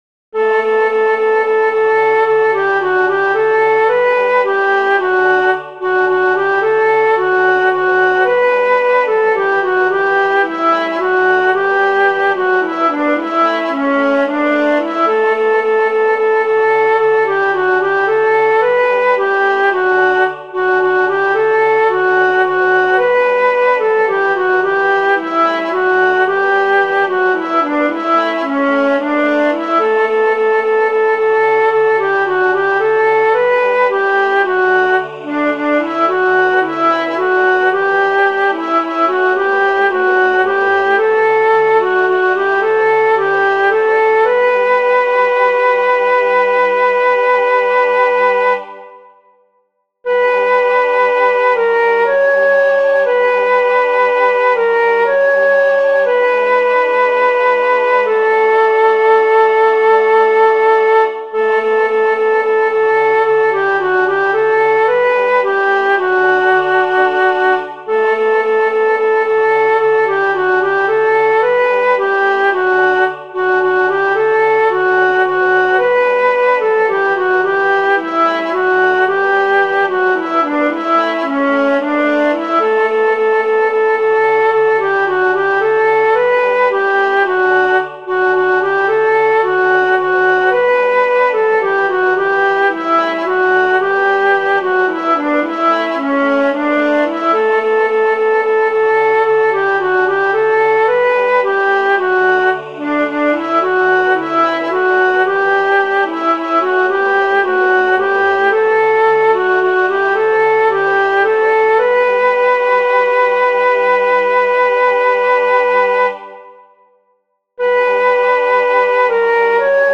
• El tempo indicado es Vivo.
Aquí os dejo los MIDI con las diferentes voces:
Sopranos: tenéis una melodía muy cómoda y pegadiza.